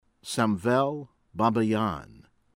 Azeris ah-ZAIR-ees